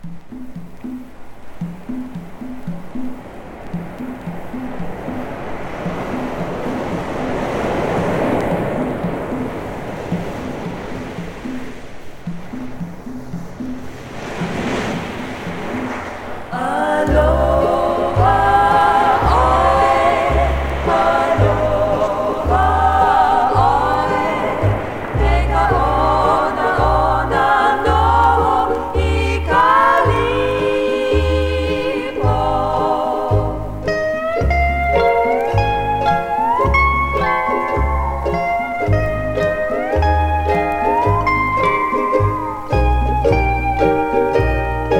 Jazz, Pop　USA　12inchレコード　33rpm　Mono